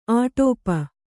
♪ āṭōpa